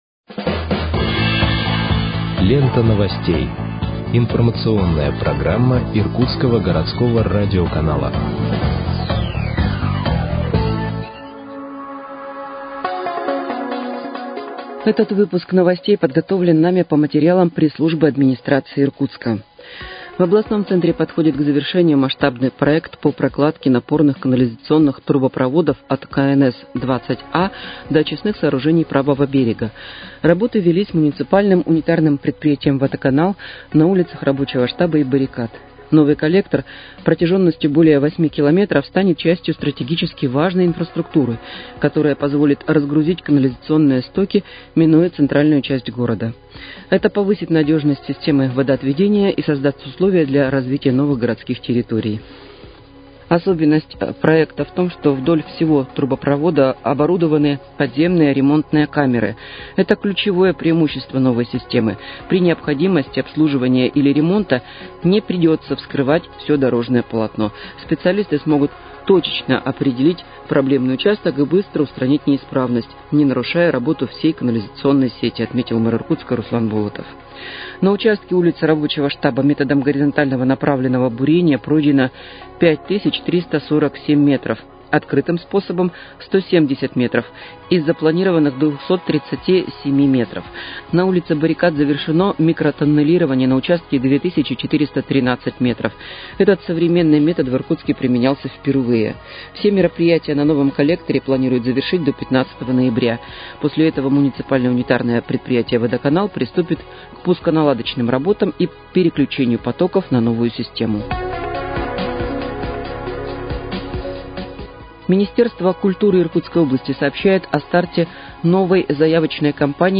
Выпуск новостей в подкастах газеты «Иркутск» от 11.11.2025 № 2